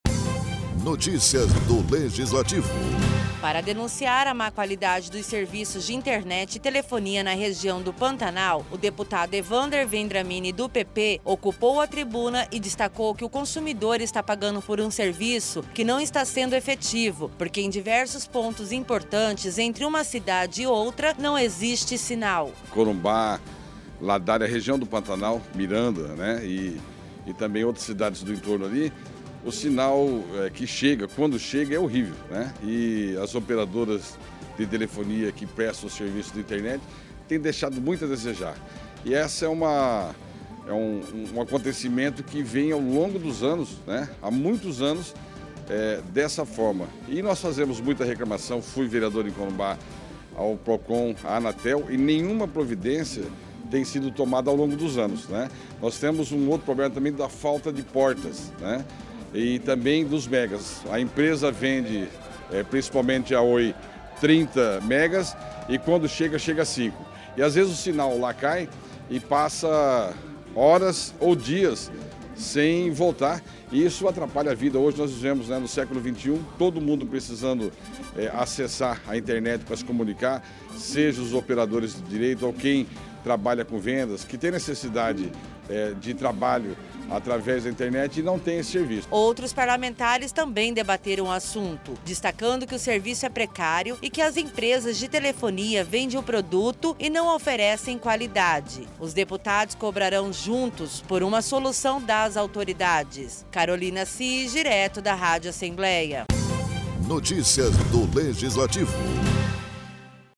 O deputado estadual Evander Vendramini, do PP usou a tribuna durante a sessão ordinária na Assembleia Legislativa de Mato Grosso do Sul para criticar o serviço de telefonia e internet na Região do Pantanal.